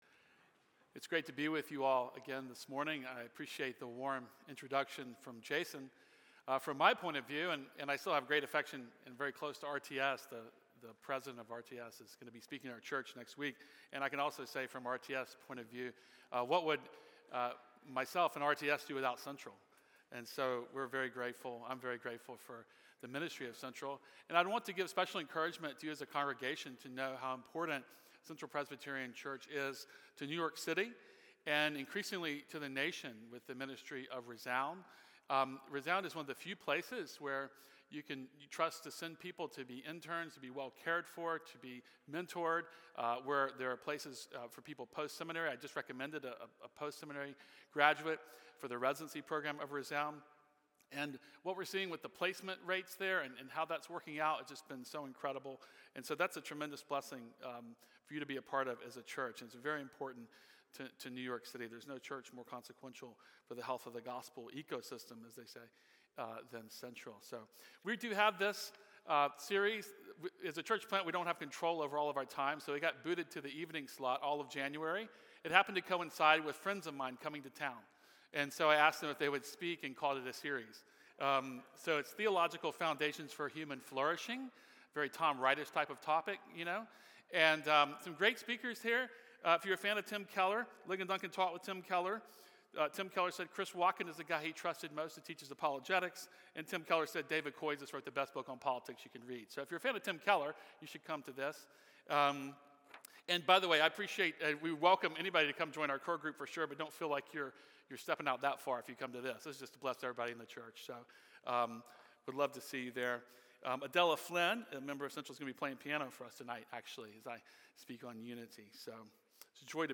Standalone Sermon: The Church as a City on a Hill in New York City